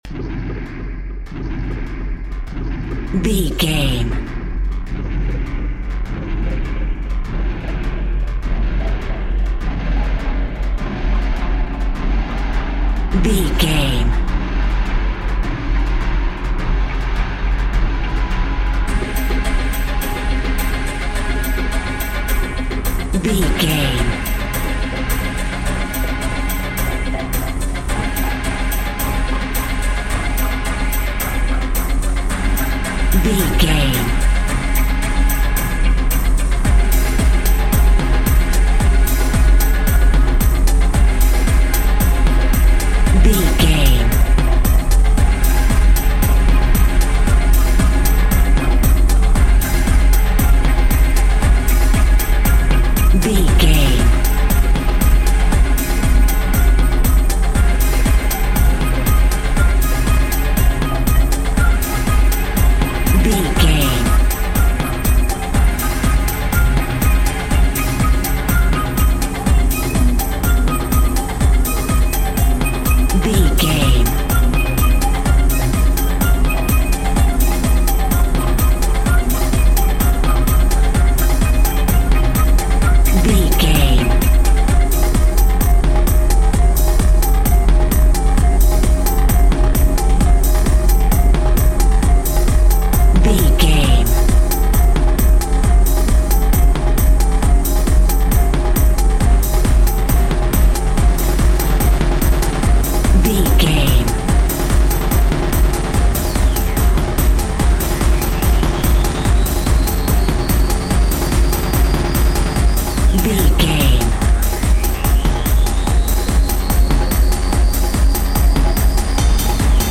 Experimental Electronica.
Epic / Action
Fast paced
In-crescendo
Aeolian/Minor
aggressive
dark
driving
intense
synthesiser
drum machine
techno
drone
glitch
synth lead
synth bass
Synth Pads